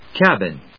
/kˈæbɪn(米国英語)/